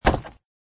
woodstone.mp3